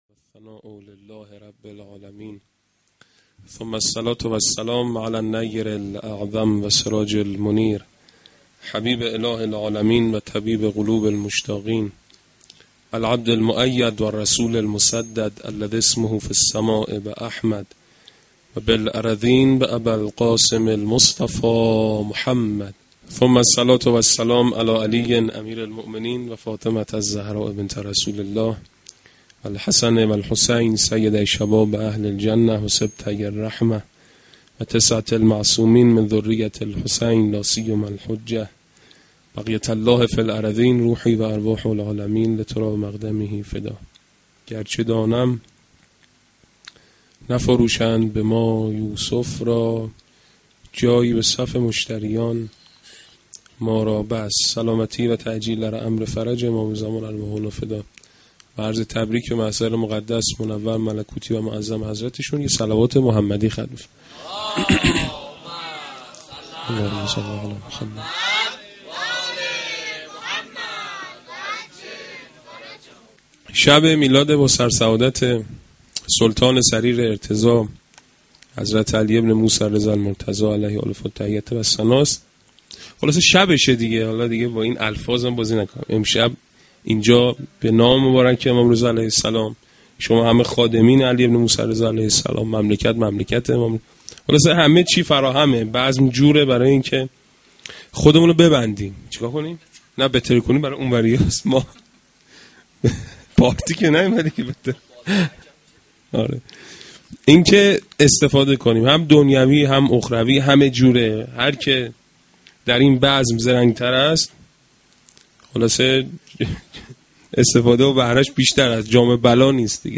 Veladate-Emam-Reza-93-Sokhanrani.mp3